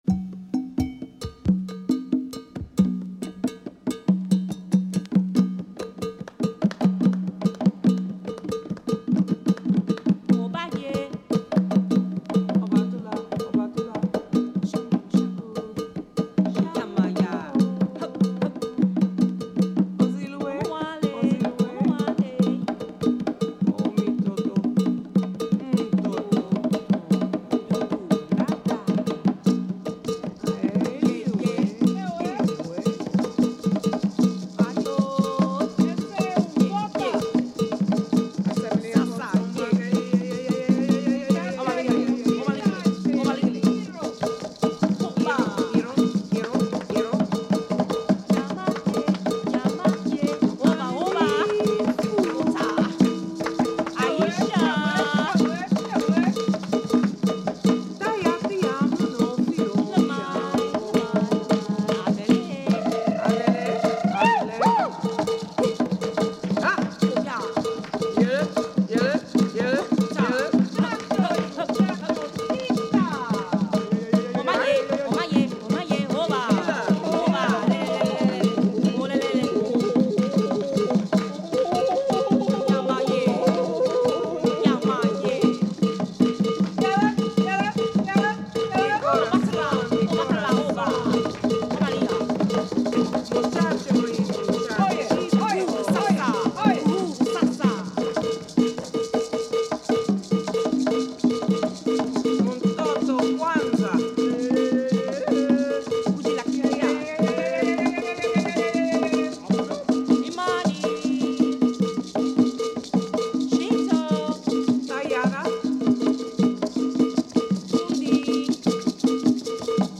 Magnificent vocal jazz